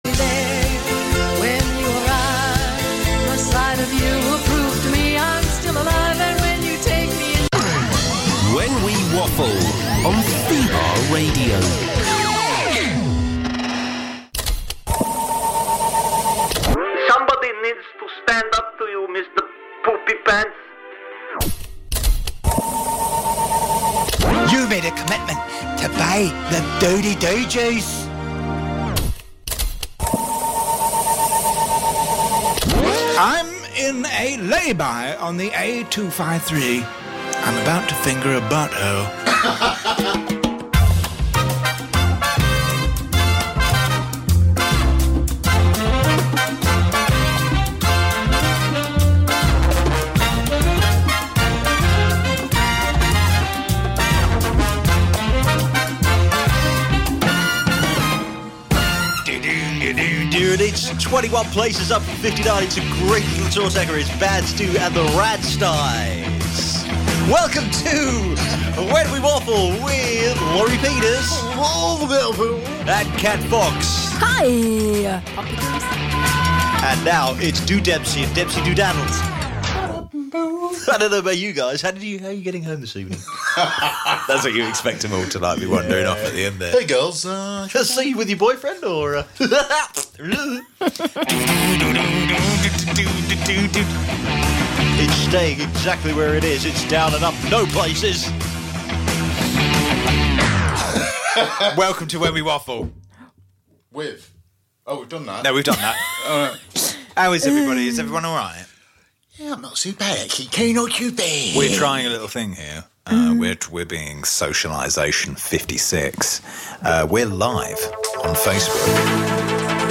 The Mimic Terry Mynott mocks beloved and less beloved stars whilst bantering with his co-hosts